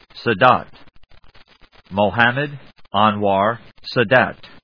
Sa・dat /sədˈæt/, Mohammed Anwar /άːnwɑɚˈænwɑː/